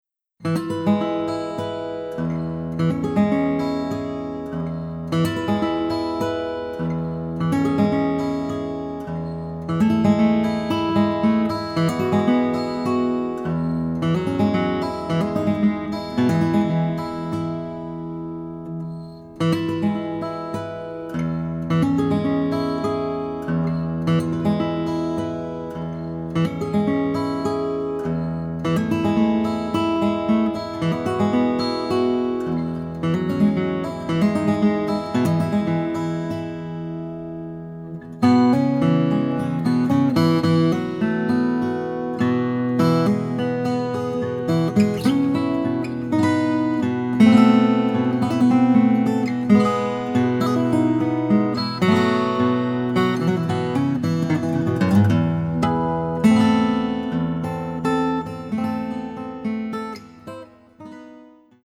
●情緒的でメロディアスなオリジナル